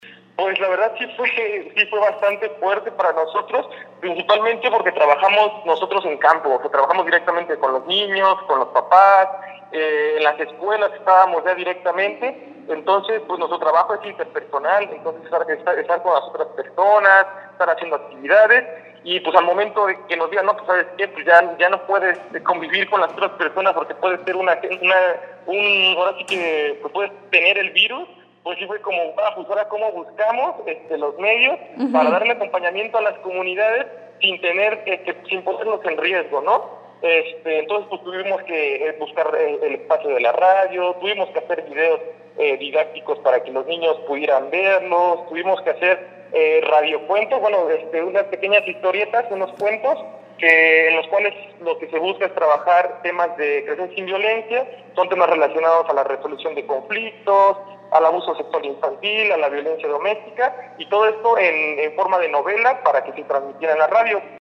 Otro año más sin festejo del día el niño (reportaje)